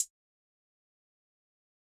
Nothing Compares Hi-Hat.wav